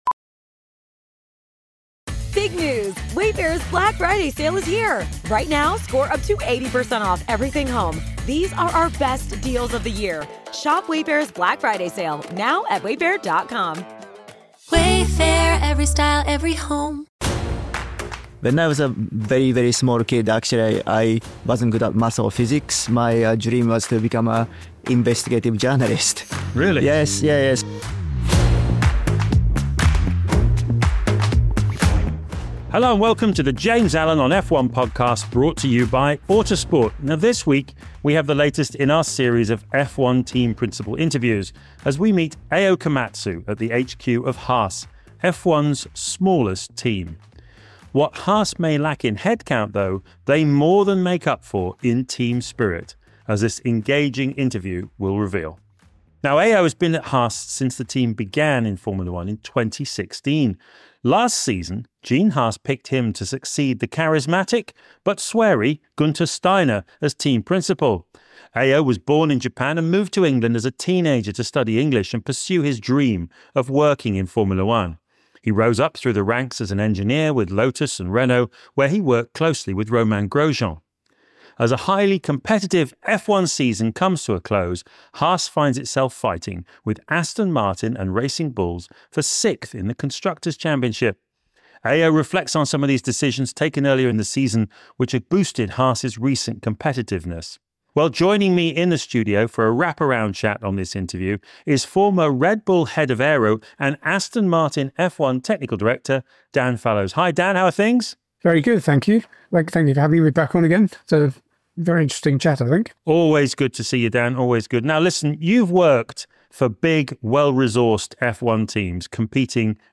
This week, we have the latest in our series of F1 team principal
Episode 58, Nov 19, 2025, 05:22 PM Headliner Embed Embed code See more options Share Facebook X Subscribe This week, we have the latest in our series of F1 team principal interviews as we meet Ayao Komatsu at the HQ of Haas, F1’s smallest team.